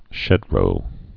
(shĕdrō)